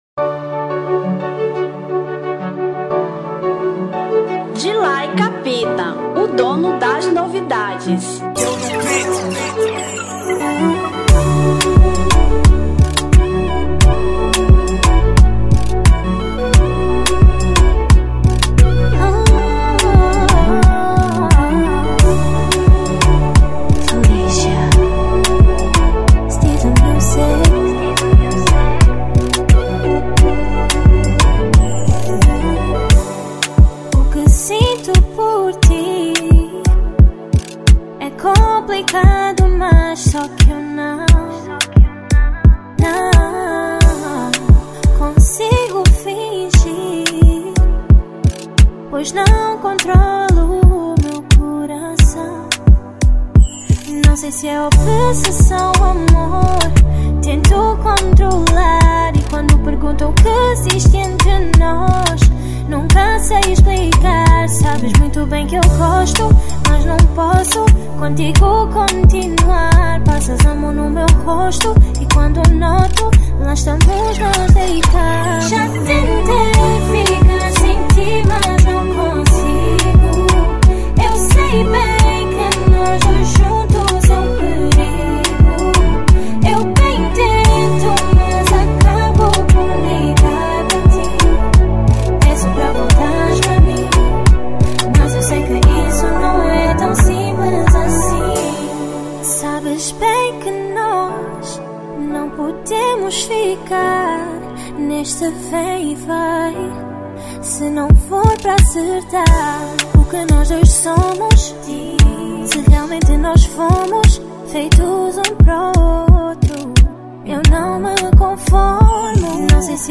Zouk 2025